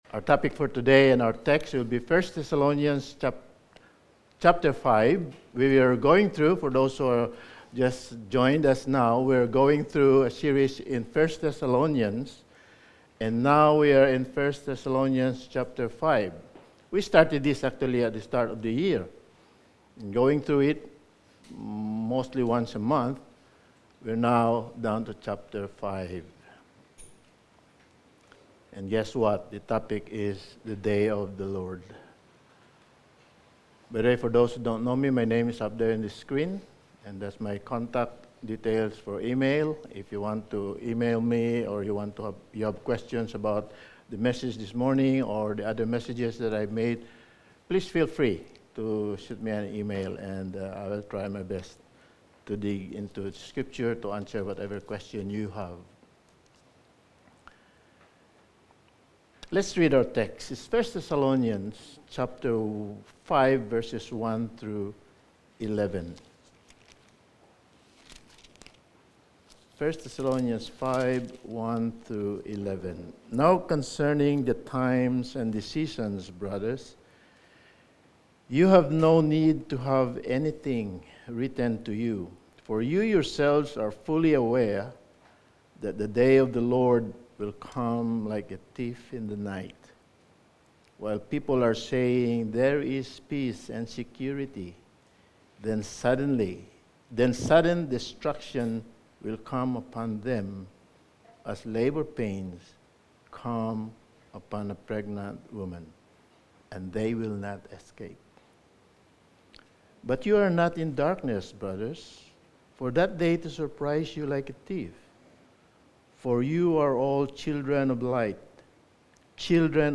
Sermon
1 Thessalonians 5:1-11 Service Type: Sunday Morning Sermon 10 « The Glory of Kingdom Mercy People Who Misses Christmas and Why?